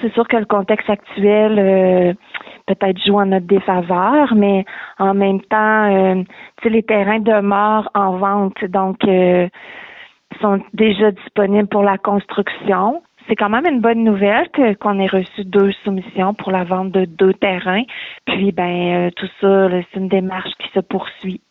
La mairesse, Lucie Allard, a donné ses commentaires à la suite du peu de soumissions reçues.